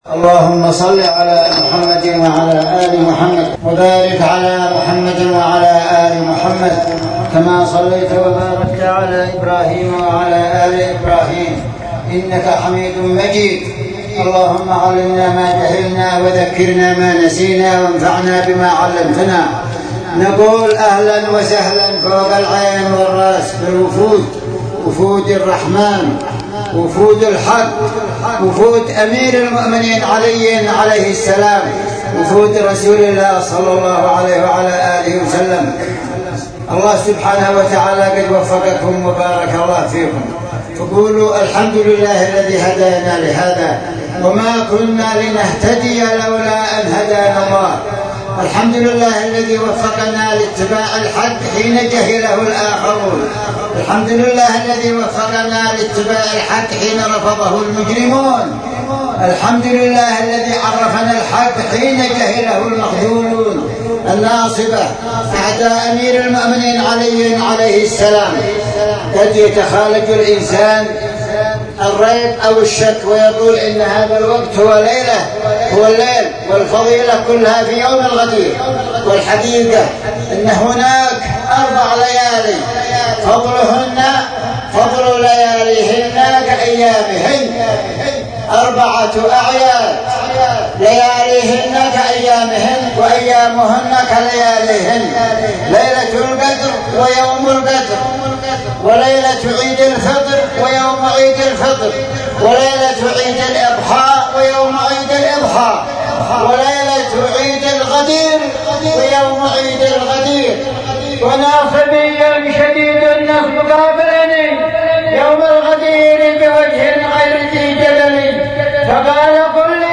كلمة المولى أيده الله في ليلة الغدير 1435 هـ.mp3